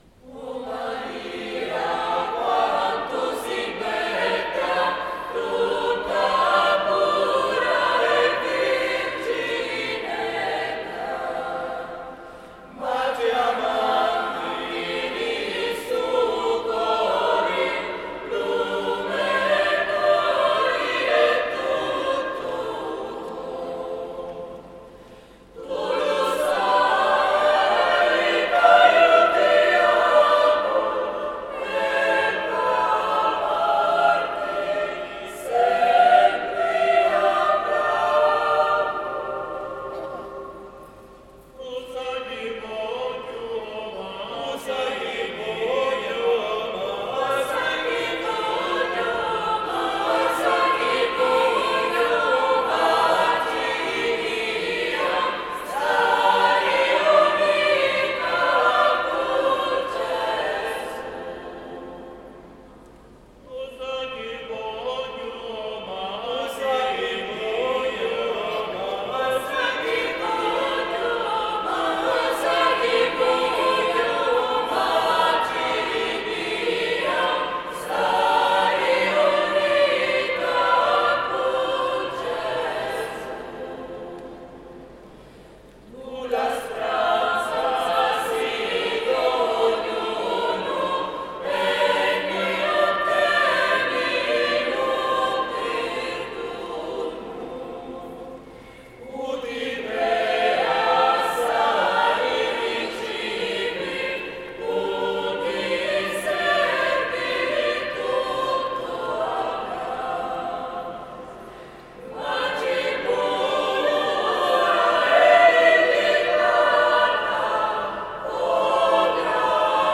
Gallery >> Audio >> Audio2017 >> Rassegna Corali Diocesane >> 13a-RassCorali 26Nov2017 SGiuseppeJato